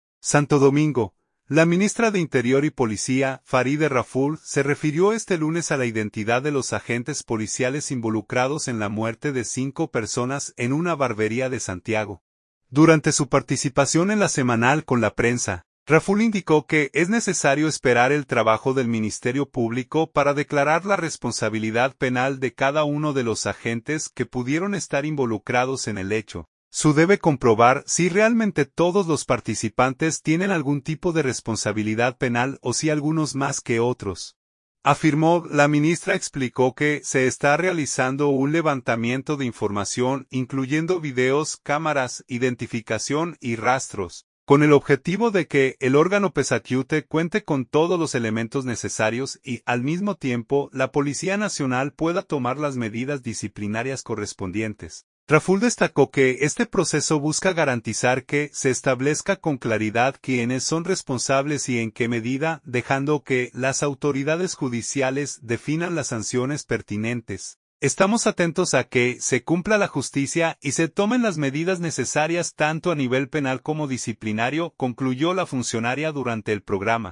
SANTO DOMINGO.– La ministra de Interior y Policía, Faride Raful, se refirió este lunes a la identidad de los agentes policiales involucrados en la muerte de cinco personas en una barbería de Santiago, durante su participación en La Semanal con la Prensa.